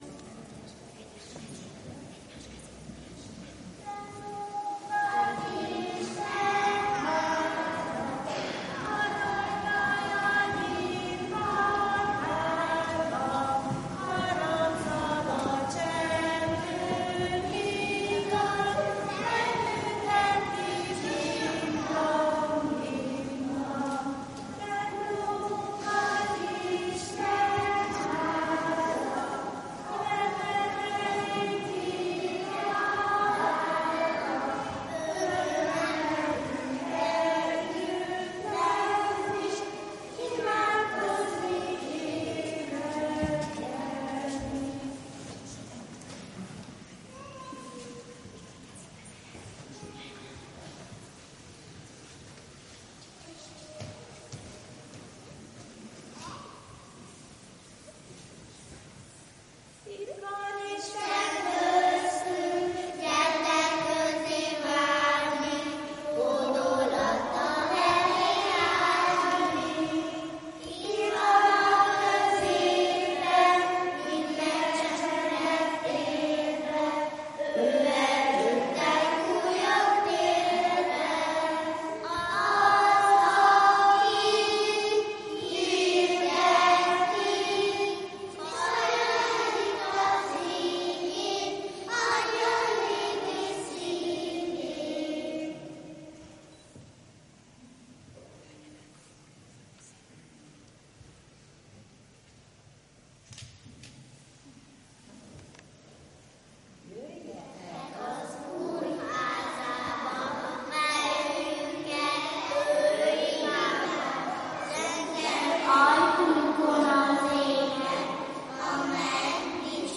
Családi istentisztelet a Jó Pásztor Református Óvoda Bárányka csoportjának szolgálatával